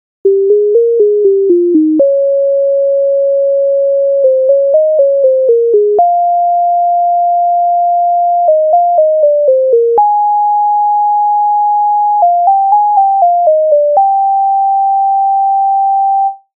MIDI файл завантажено в тональності Es-dur